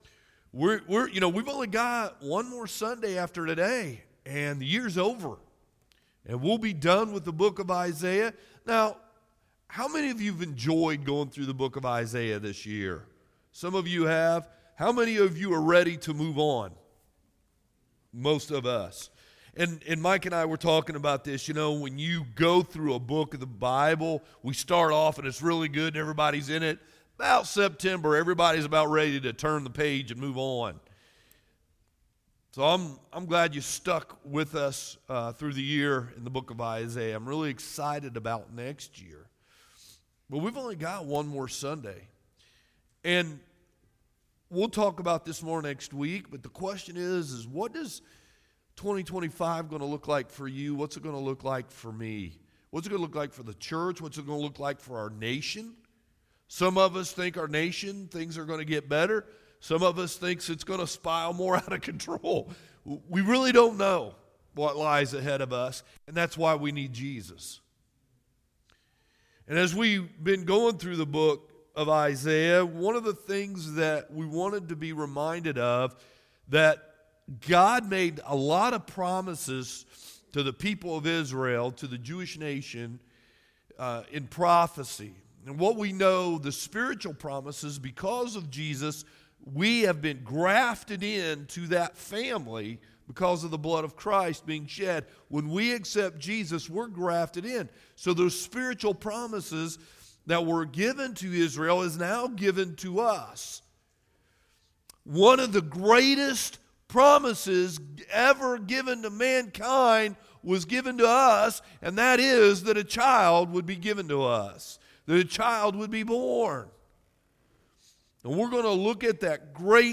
Sermons | Old Town Hill Baptist Church